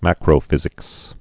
(măkrō-fĭzĭks)